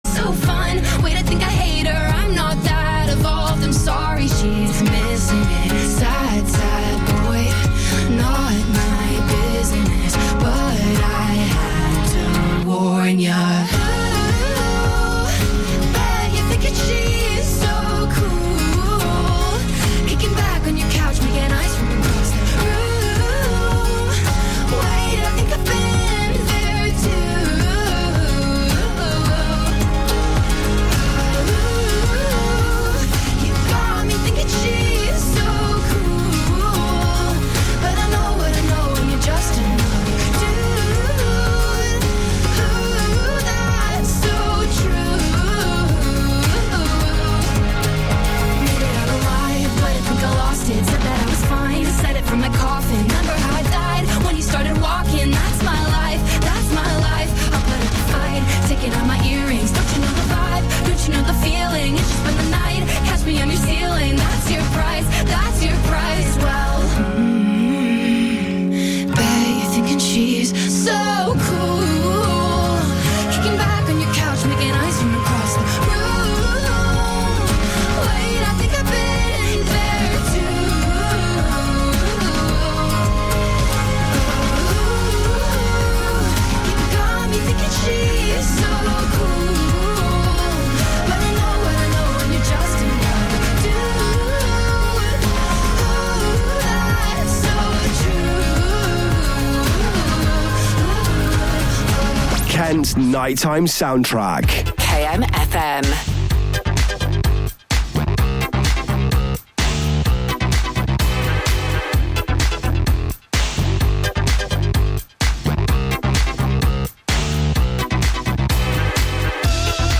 Radio station
Genre: Classic hits , Talk , Variety